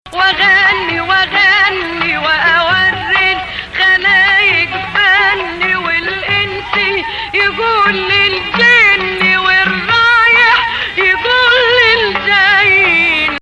Hijaz 5
between Sikah 3 & Hijaz 5 here.